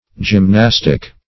Gymnastic \Gym*nas"tic\ (j[i^]m*n[a^]s"t[i^]k), Gymnastical